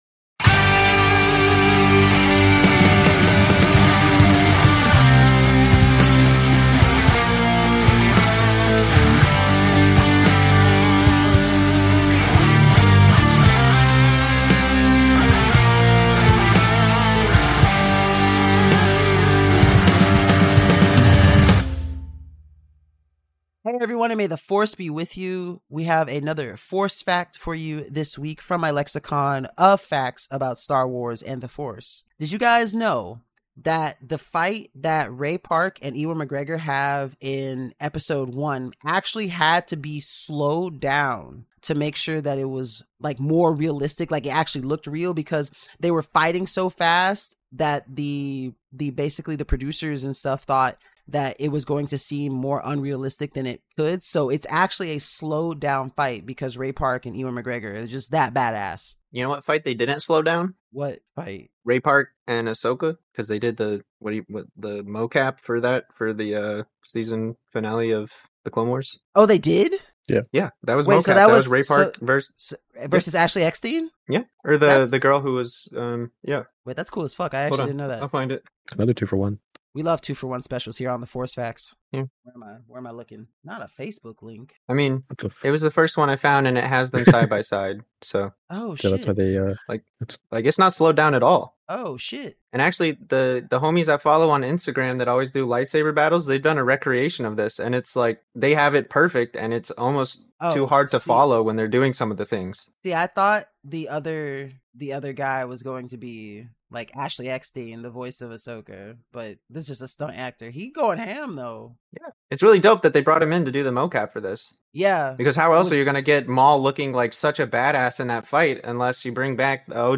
Welcome to The Force Unscripted Podcast, where Star Wars fans share unfiltered discussions, fresh insights, and genuine camaraderie in exploring the galaxy far, far away.